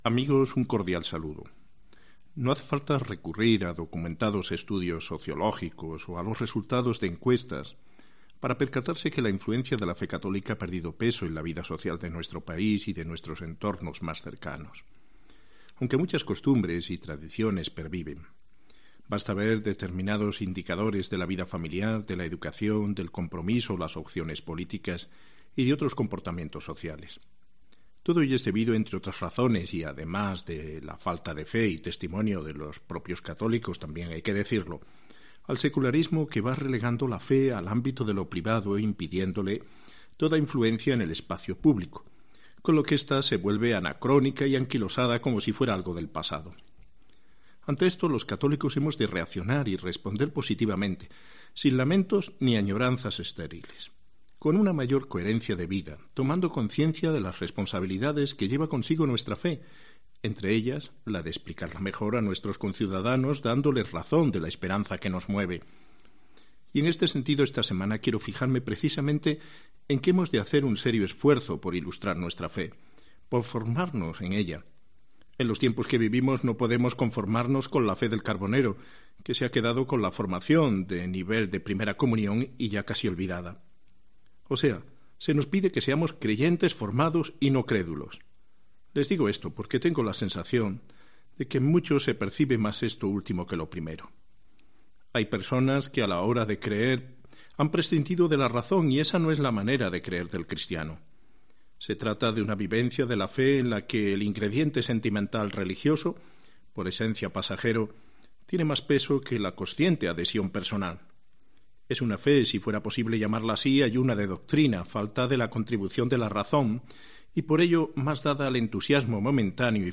Mensaje de Mons. Gil Tamayo del domingo 3 febrero 2019
Obispo de Ávila